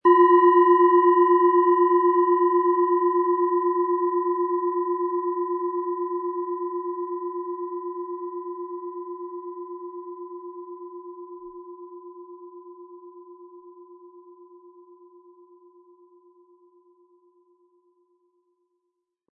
Planetenschale® Freiraum erschaffen & Energie entfachen mit Wasser-Ton, Ø 11,9 cm, 180-260 Gramm inkl. Klöppel
Wasser
SchalenformBihar
HerstellungIn Handarbeit getrieben
MaterialBronze